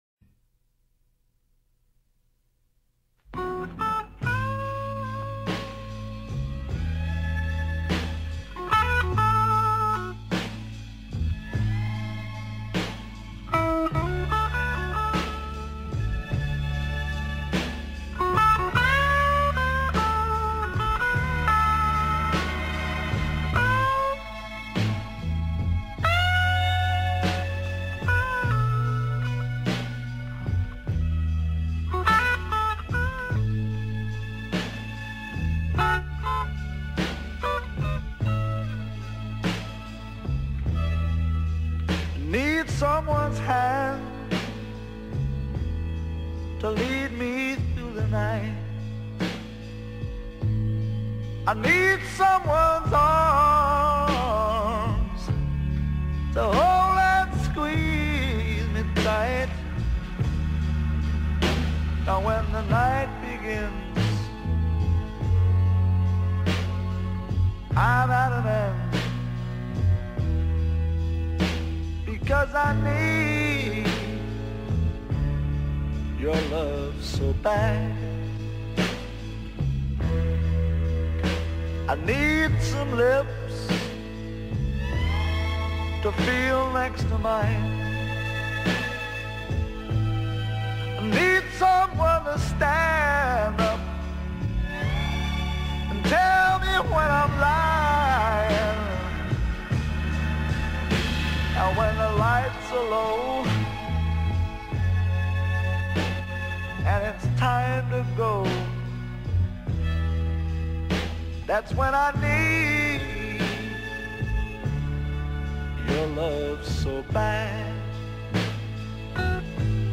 Blues Rock, Classic Rock